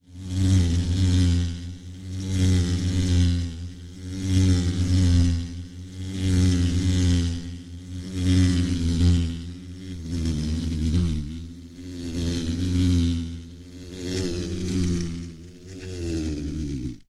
Мультипликационная версия с полетом пчелы